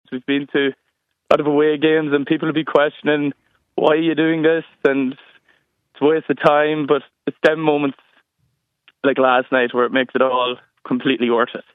Thousands of Ireland fans booked last minute flights to be in the stadium for the game this weekend - this supporter says it was worth the trip: